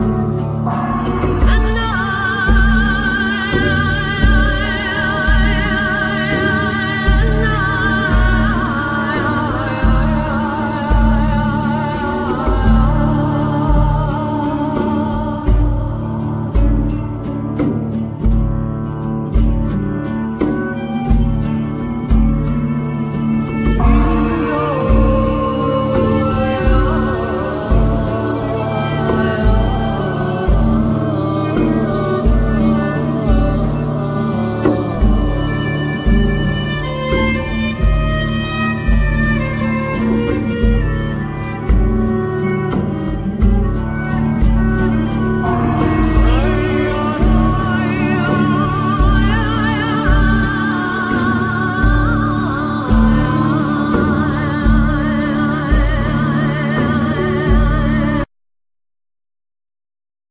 Electronics,Sampler,Voices
Violin
Daouli